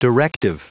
Prononciation du mot directive en anglais (fichier audio)
Prononciation du mot : directive